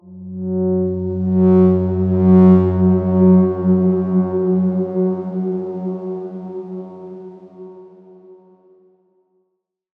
X_Darkswarm-F#2-f.wav